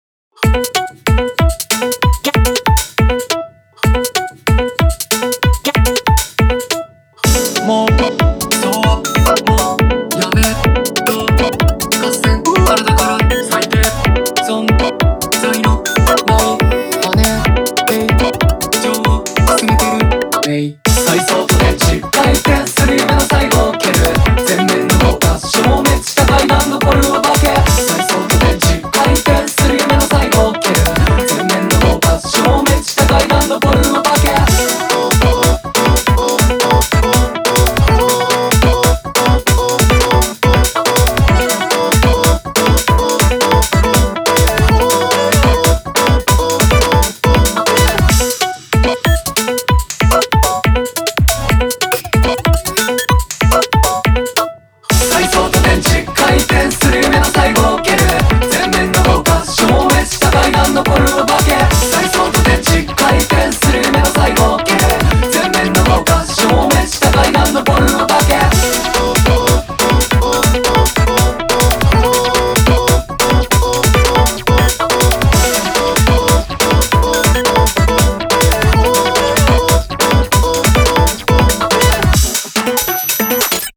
BPM141
Audio QualityPerfect (High Quality)
vocal pop piece